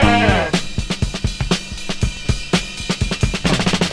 • 122 Bpm Drum Loop Sample G# Key.wav
Free breakbeat - kick tuned to the G# note. Loudest frequency: 1607Hz
122-bpm-drum-loop-sample-g-sharp-key-74G.wav